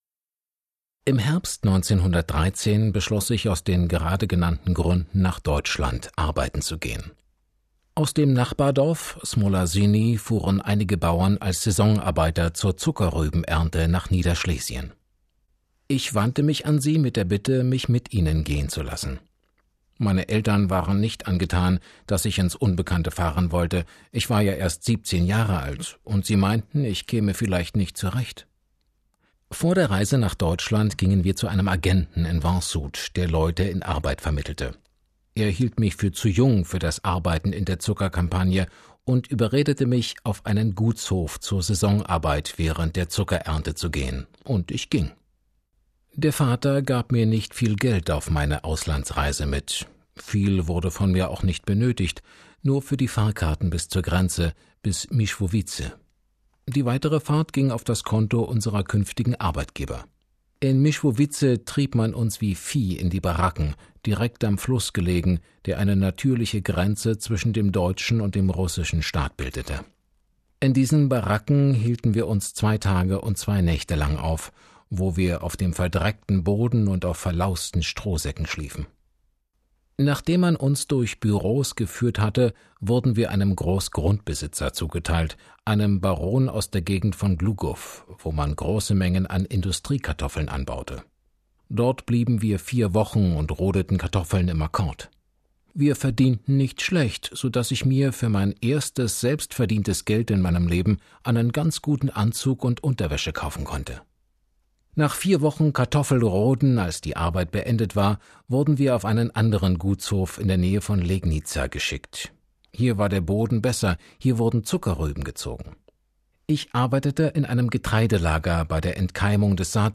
Auszüge aus dem Bericht eines galizischen Saisonarbeiters